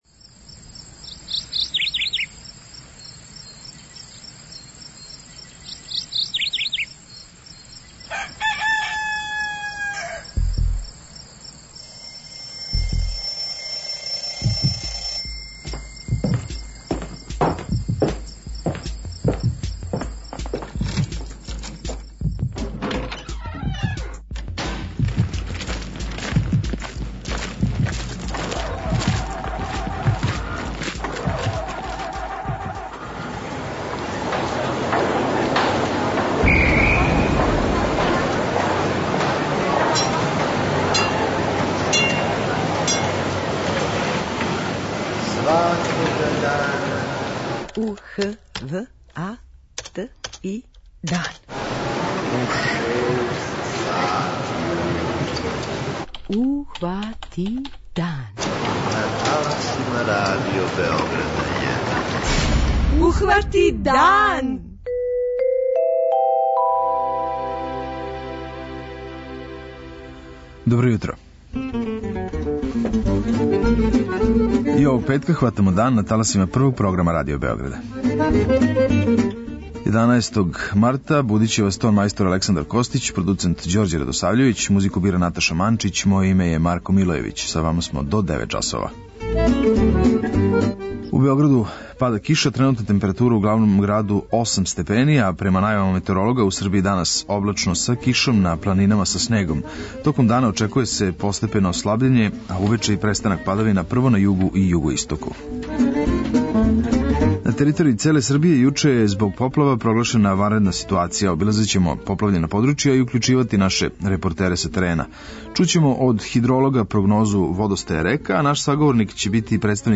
Наши репортери уклучују се са терена.